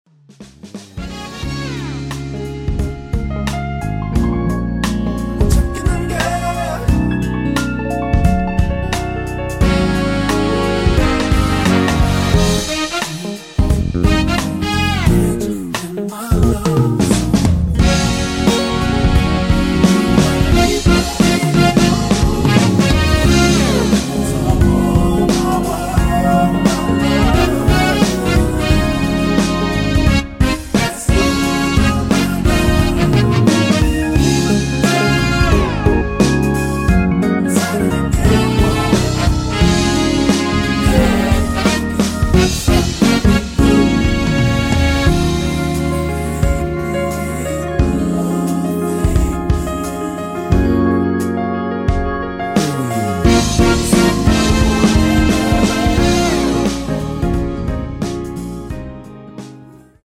(-2)내린 코러스 포함된 MR 입니다.(미리듣기 참조)
Db
앞부분30초, 뒷부분30초씩 편집해서 올려 드리고 있습니다.
중간에 음이 끈어지고 다시 나오는 이유는